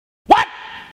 Myinstants > 사운드 > Sound Effects > WHAAATTT???